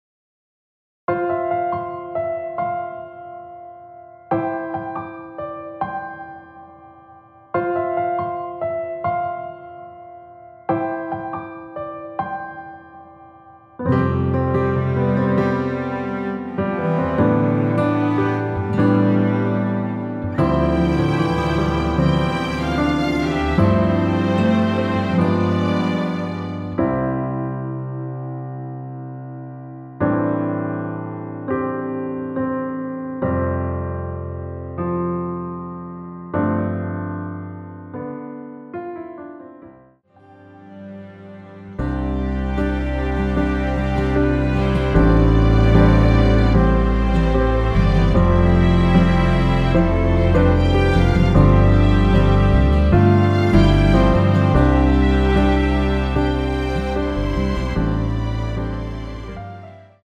원키에서(-1)내린 MR입니다.
앞부분30초, 뒷부분30초씩 편집해서 올려 드리고 있습니다.